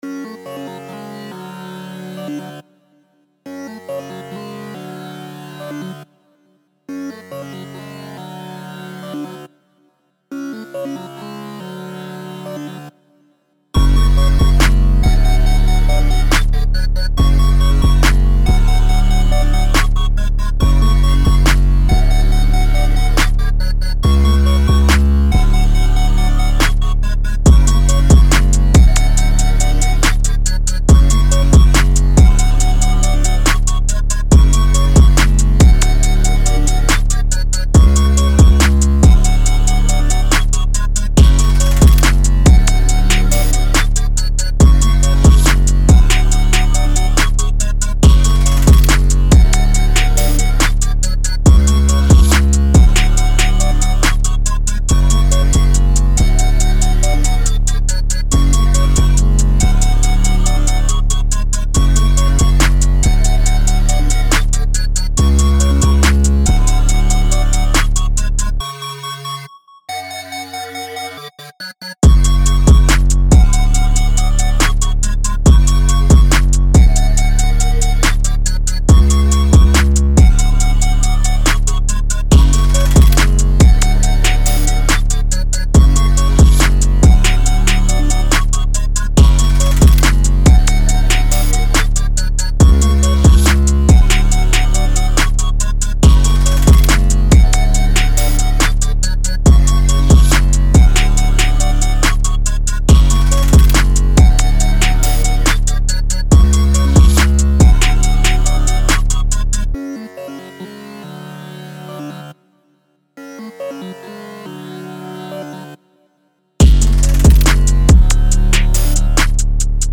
Жанр: Hip-Hop,Drill
New Jazz Энергичный 140 BPM